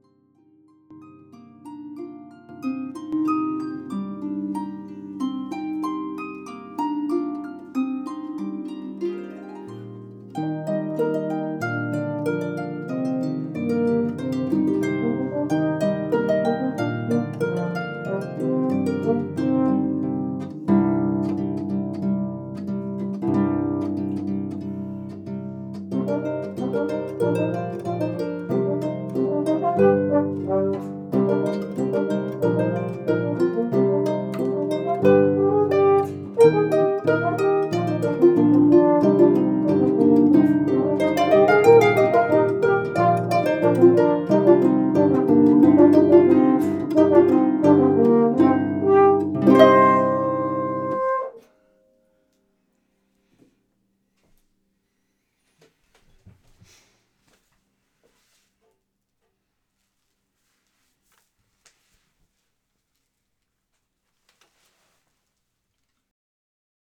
This suite of six arrangements for horn and pedal harp